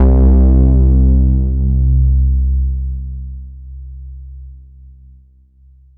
VEC1 Bass Long 21 C.wav